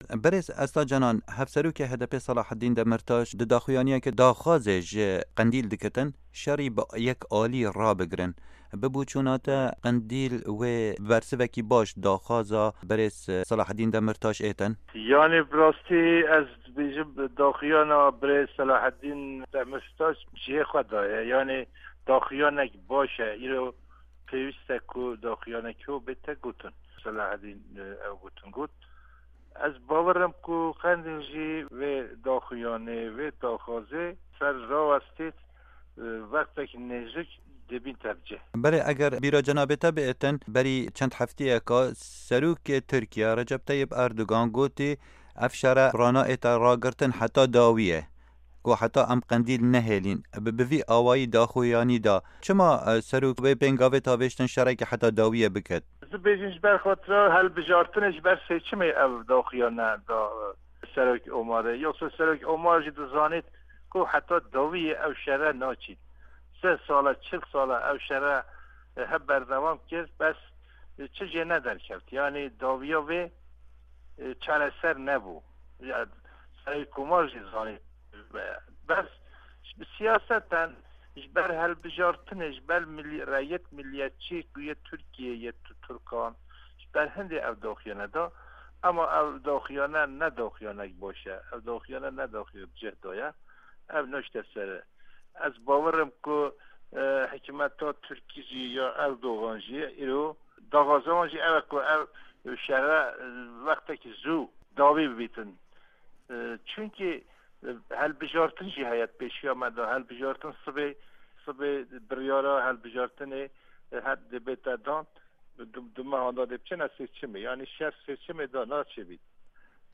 Hevpeyvin digel Esta Cenan